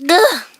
ooff2.wav